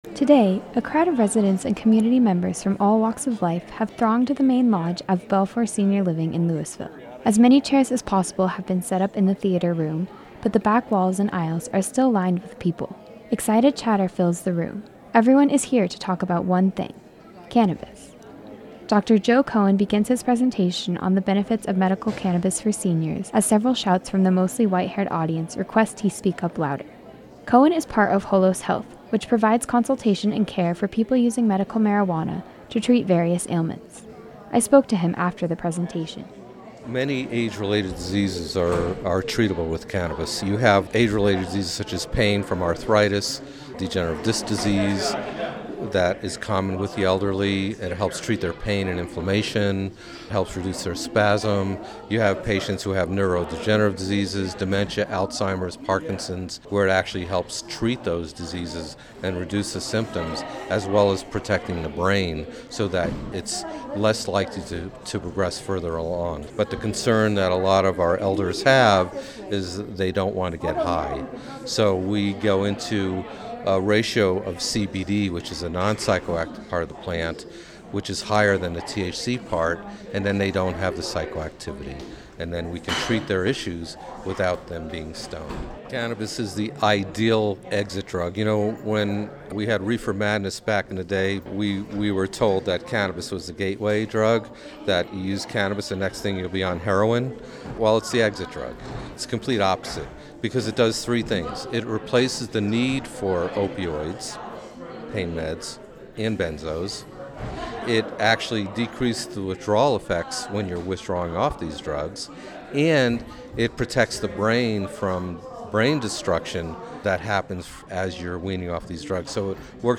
several shouts from the mostly white-haired audience request he speak up louder.